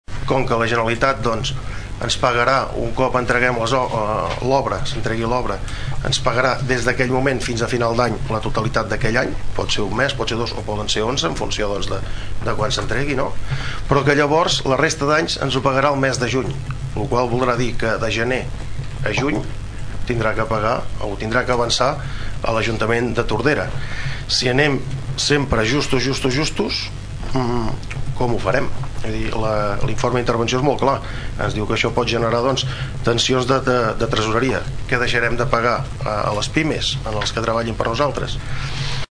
Josep Romaguera, del PSC va alertar que la despesa que l’ajuntament farà per pagar els primers anys de les obres, poden causar tensions de tresoreria.